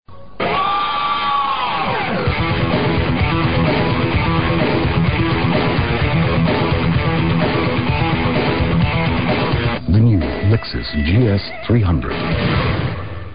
赏析：极具饱和度的色彩，动感十足的音乐，让人过目不忘，印象尤为深刻。